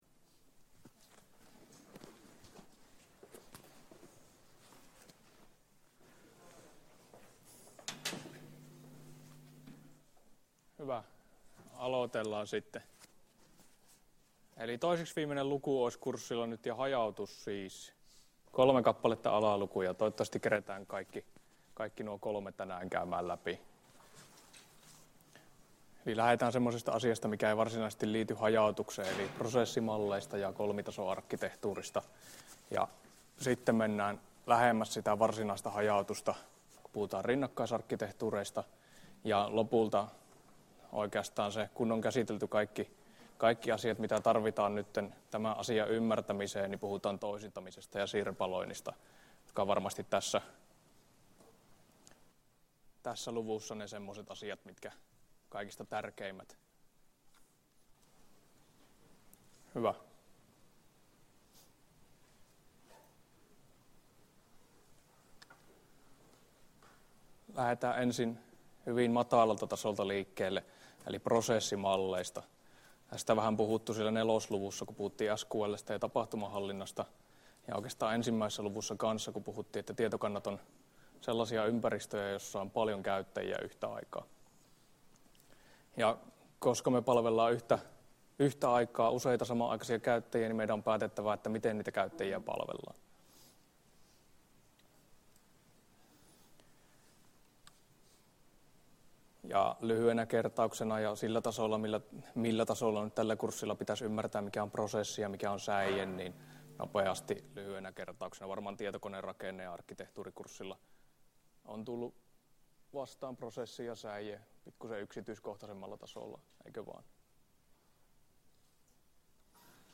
Luento 15 — Moniviestin